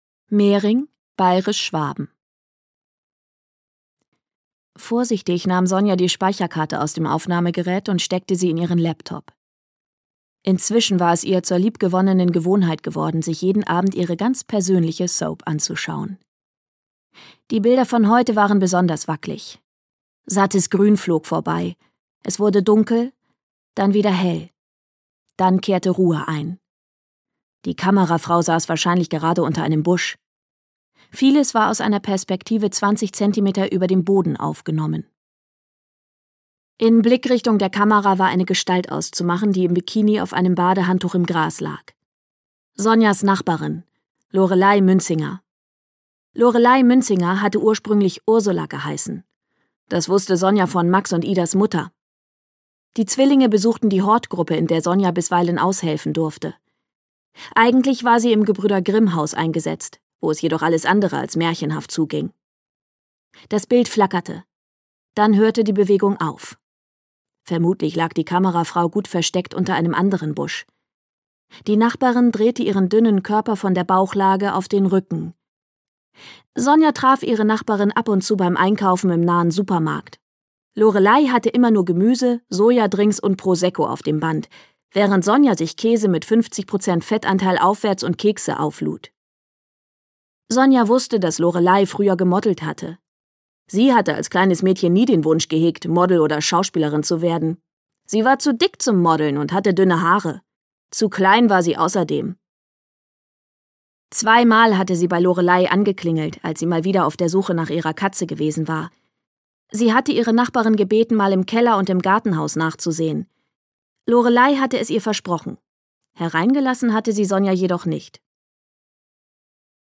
Glück ist nichts für Feiglinge - Nicola Förg - Hörbuch